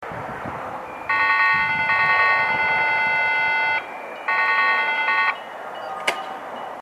gakunan-enoo-hassya.mp3